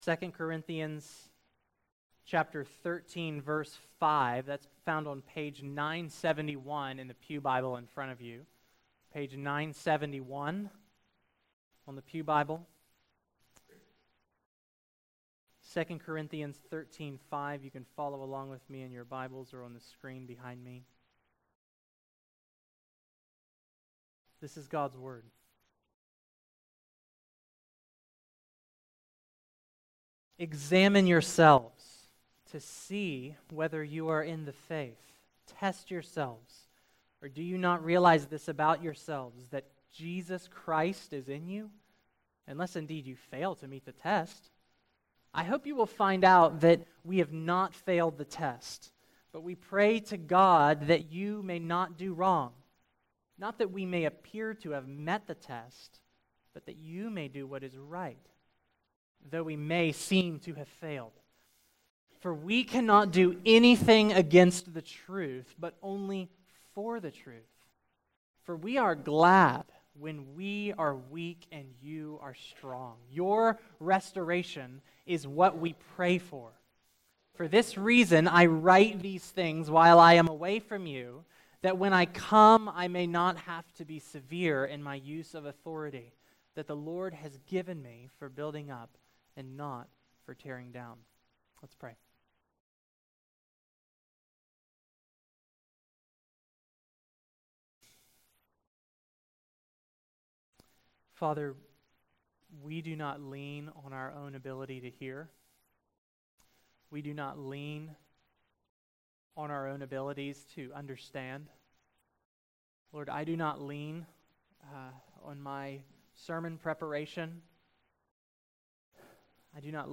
Today’s sermon continues the sermon series through 2nd Corinthians .